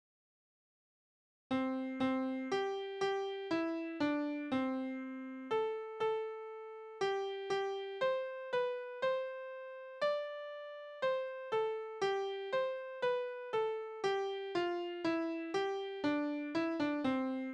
Balladen: Das Teufelsross
Tonart: C-Dur
Taktart: C (4/4)
Tonumfang: große None
Besetzung: vokal